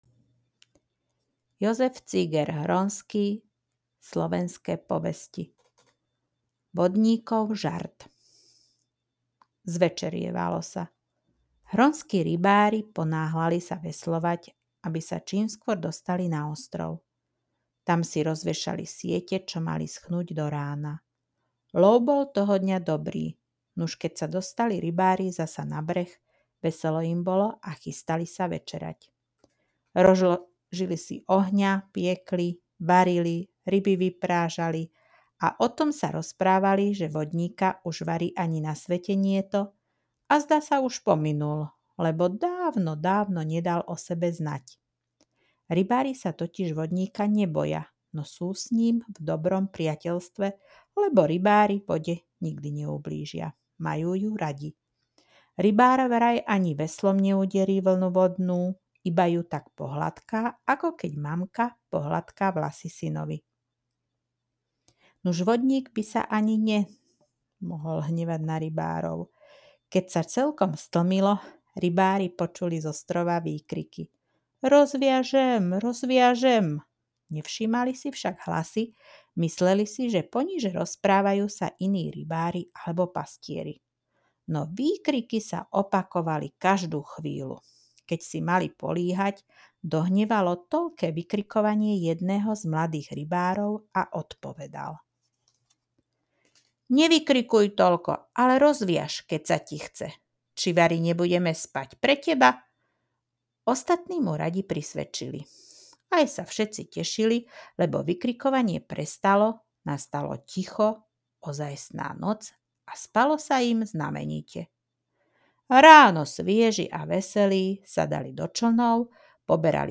Povesť Vodníkov žart z knihy Slovenské povesti číta